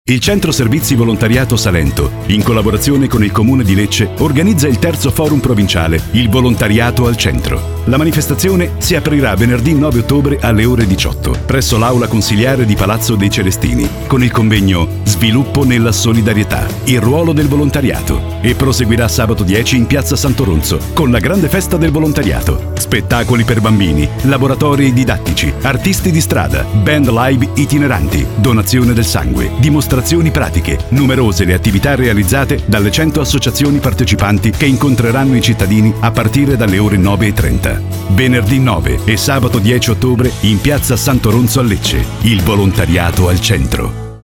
Documenti allegati: Spot Volontariato al Centro